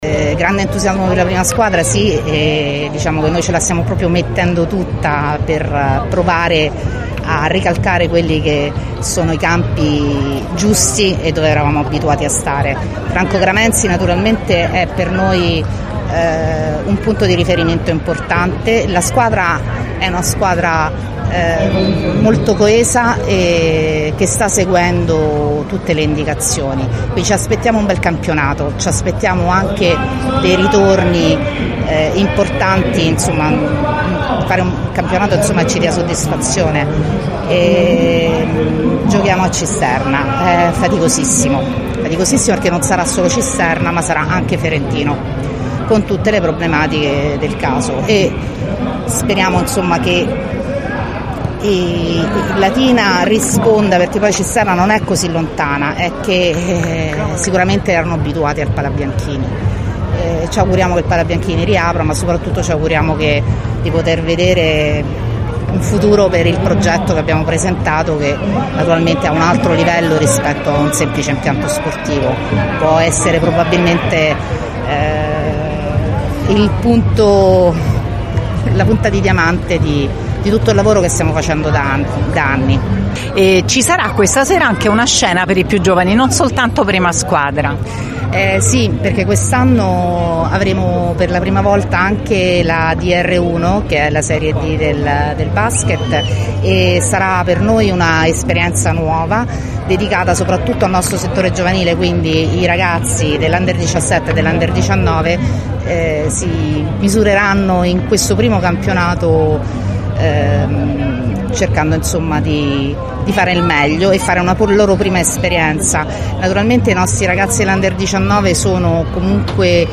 Festa in piazza del Popolo per la presentazione